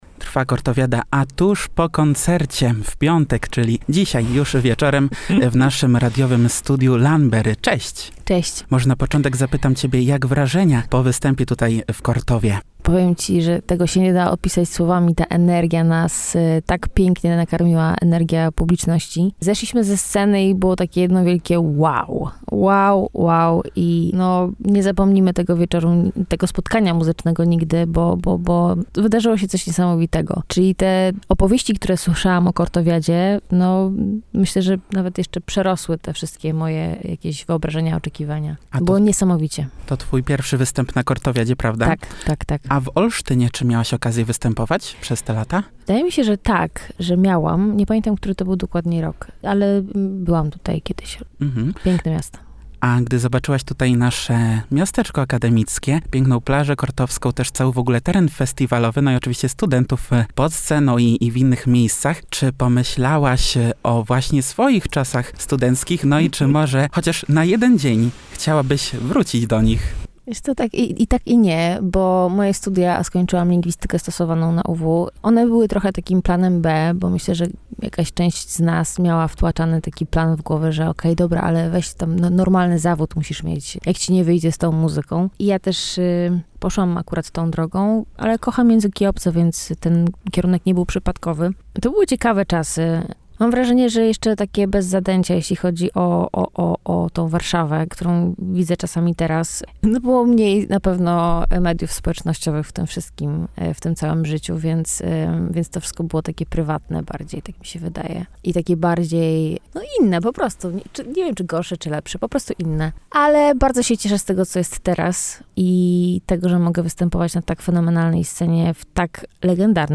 Tuż po koncercie odwiedziła studio Radia UWM FM.
Lanberry-wywiad-Kortowiada-2025.mp3